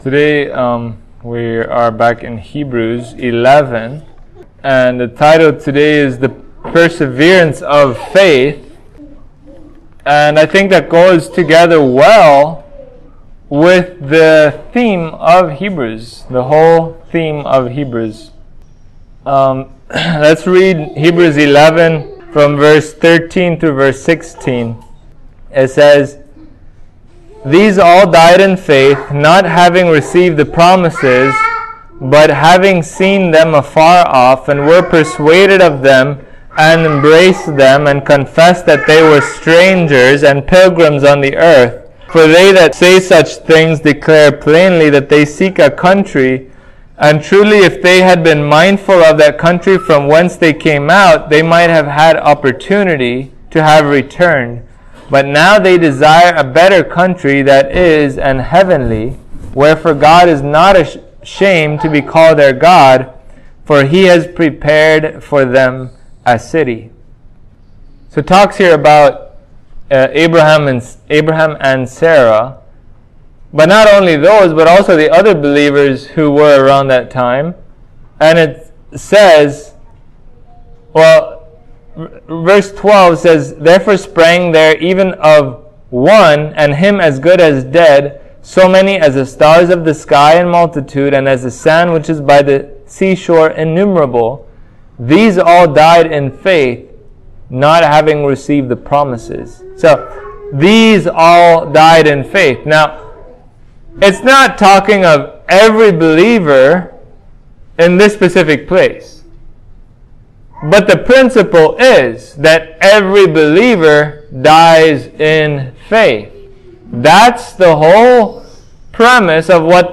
Hebrews 11:13-16 Service Type: Sunday Morning Faith looks not to the seen